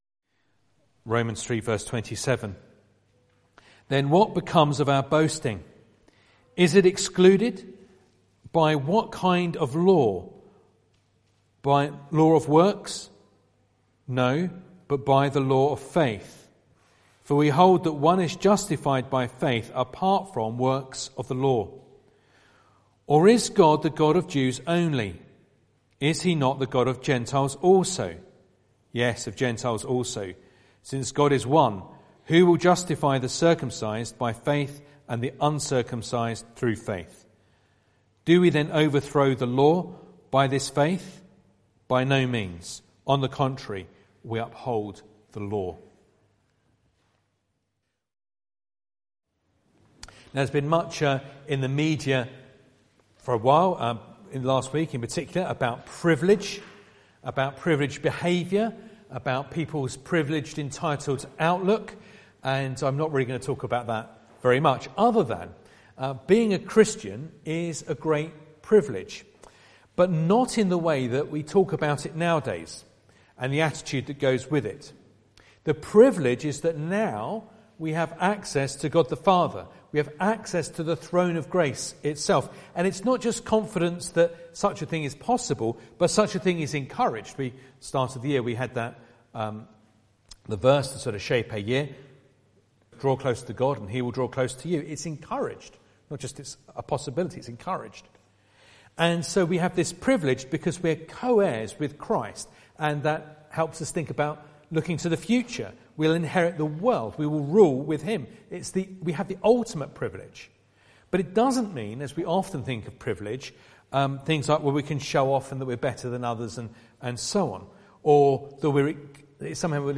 Romans 3:27-31 Service Type: Sunday Evening Bible Text